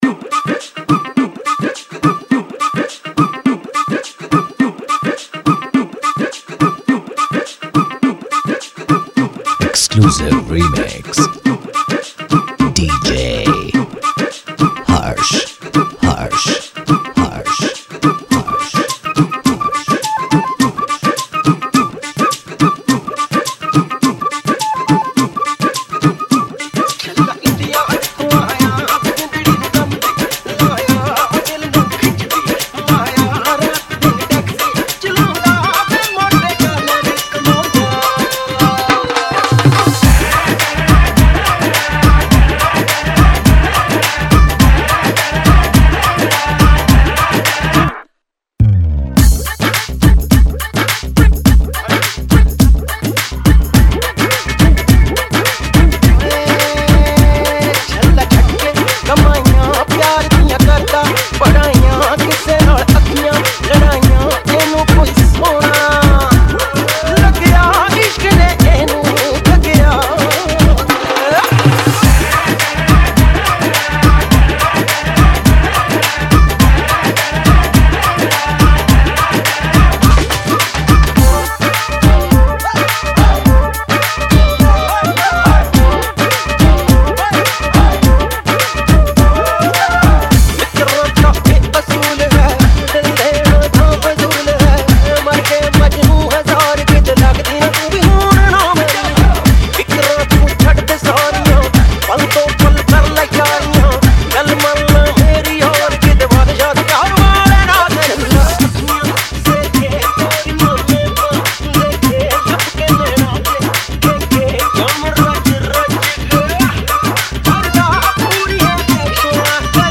Latest Dj Mixes